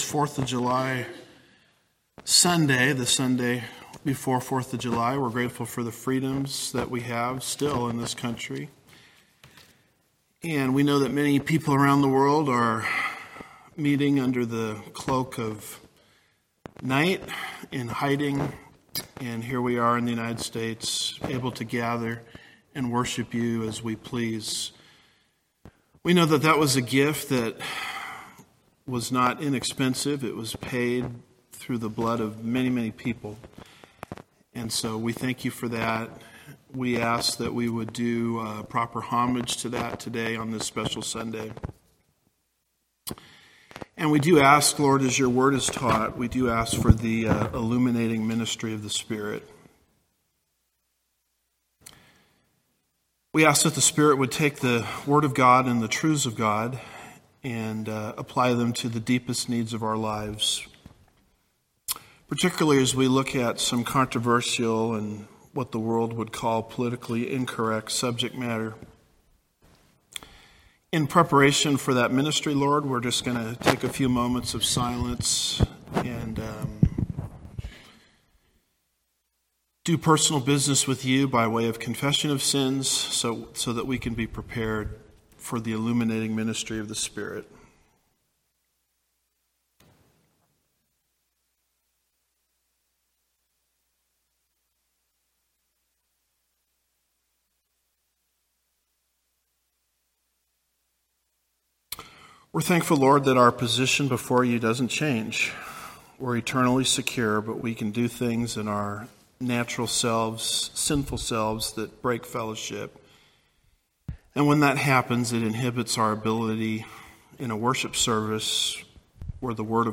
– Part 1 Home / Sermons / Is America Under the Judgment of God?